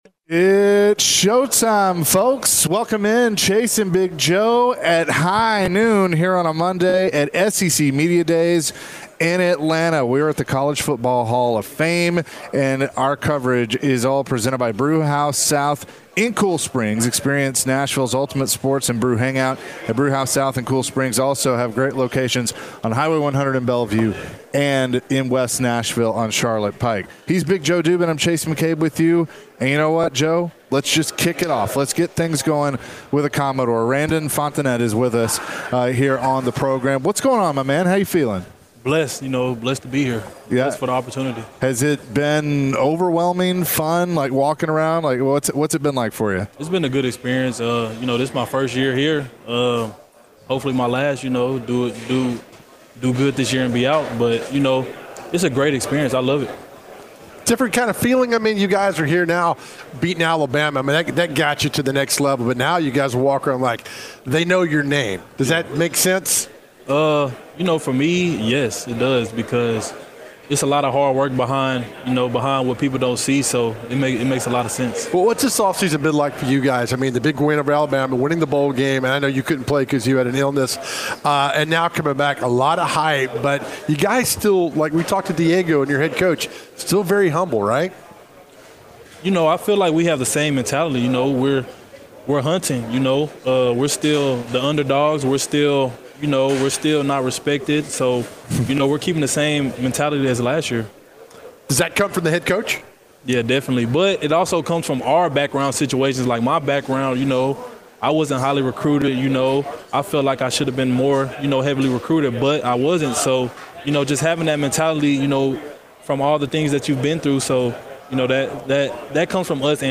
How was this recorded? the guys were LIVE from Atlanta for SEC Media Days.